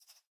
rabbit_idle1.ogg